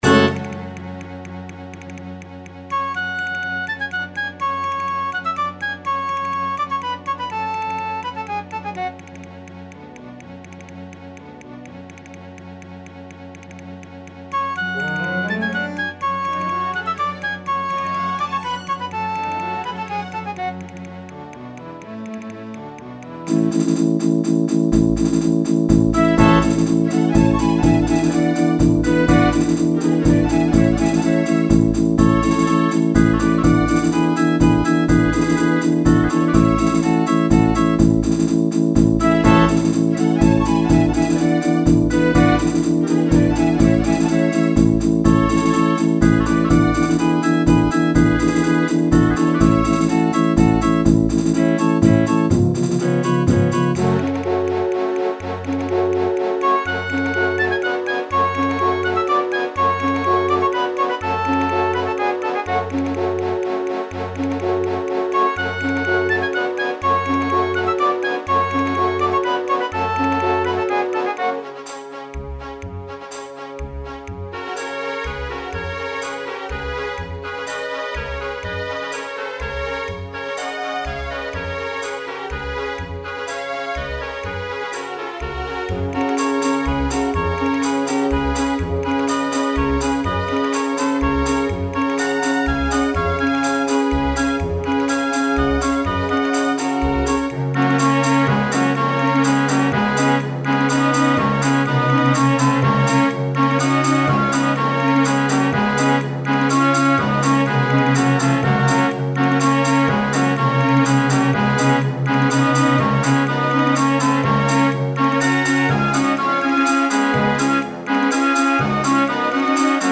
パート譜作成の基となったスコアの演奏です。